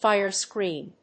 アクセントfíre scrèen